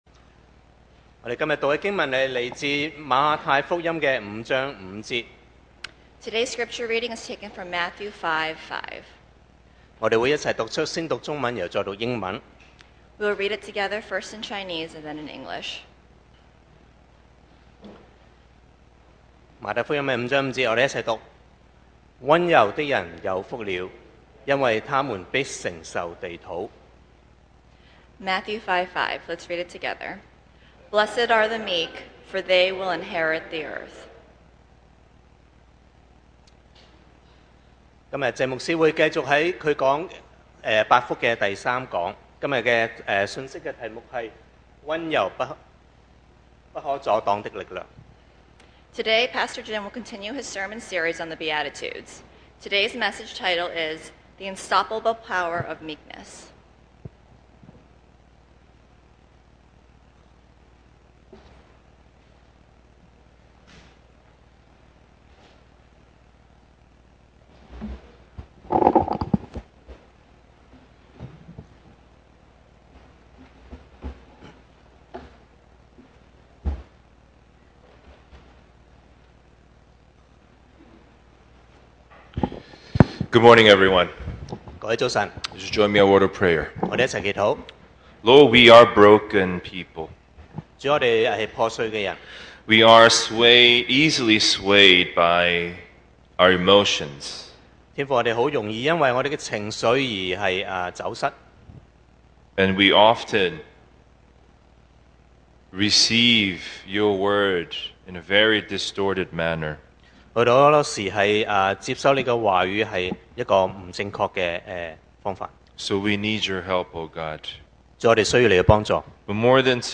Series: 2025 sermon audios
Service Type: Sunday Morning